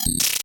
SFX音效